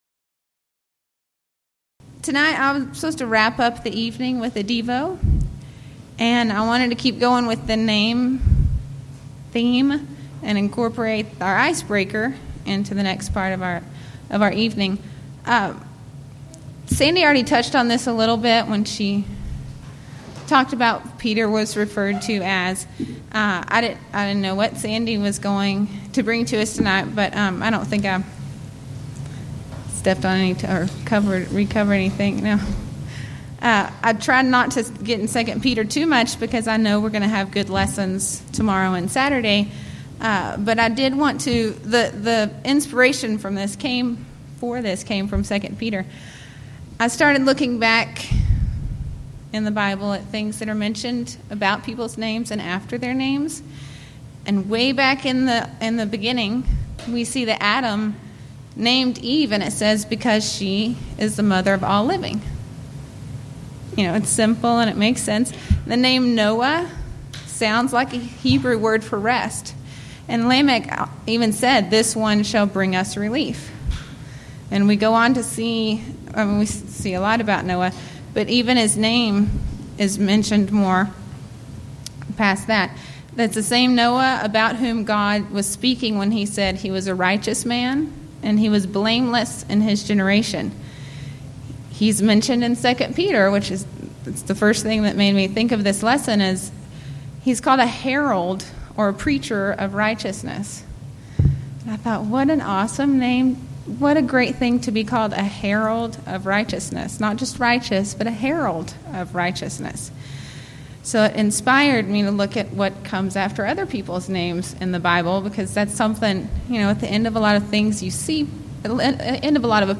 Event: 4th Annual Texas Ladies in Christ Retreat Theme/Title: Studies in 2 Peter